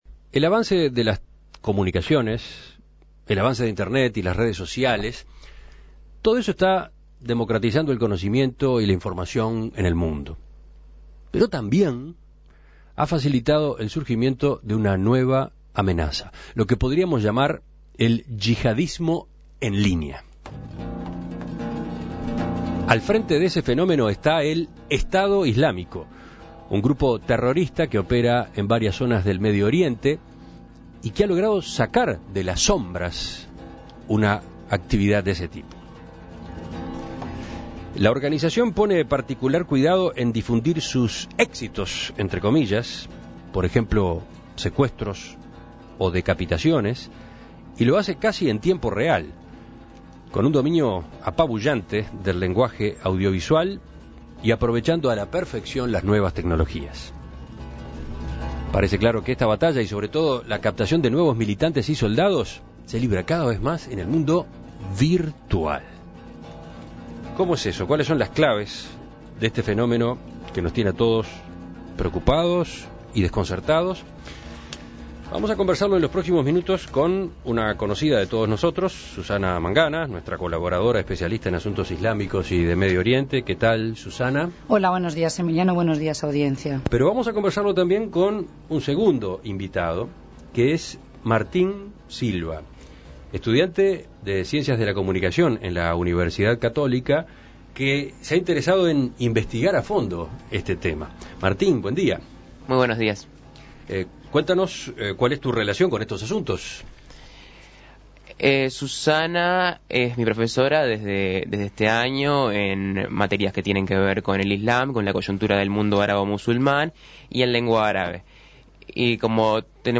Ambos estuvieron en En Perspectiva para conversar sobre este proyecto, que presentarán la semana que viene en la Universidad Nacional de La Plata.